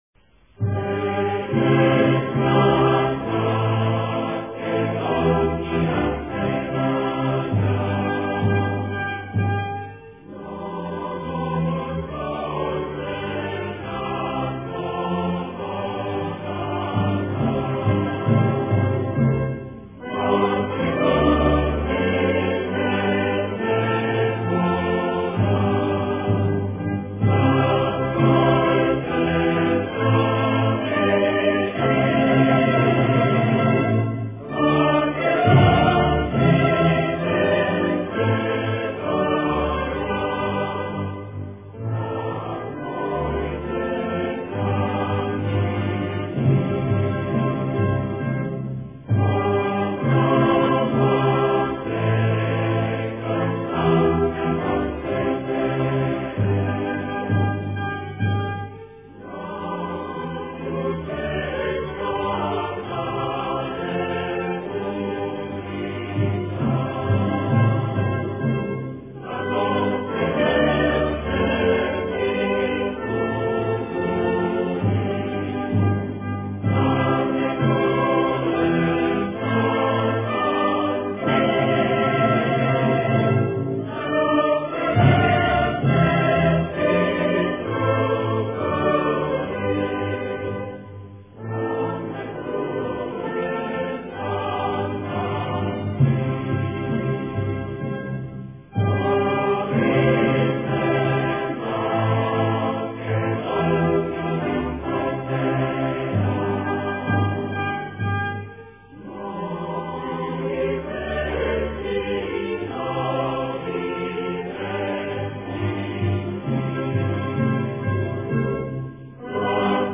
Himna R. Makedonje – vokalno-instrumentalna verzija